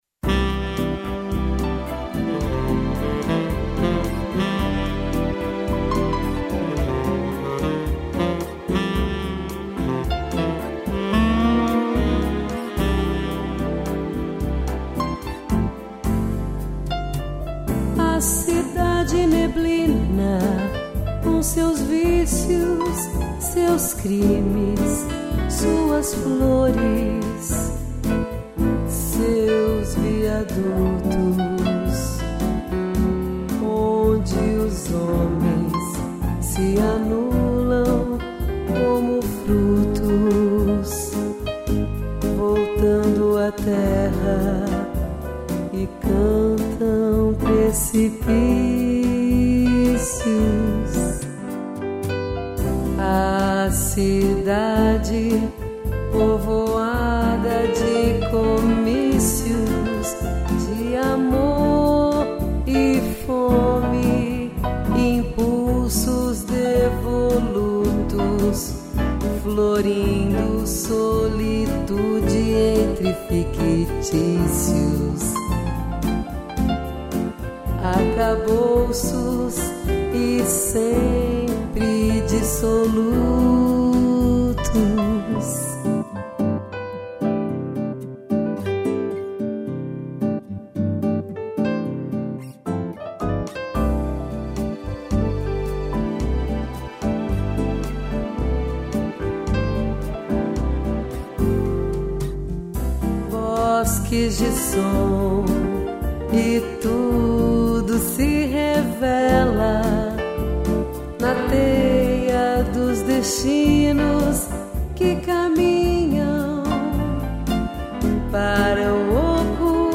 voz
piano